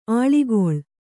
♪ āḷigoḷ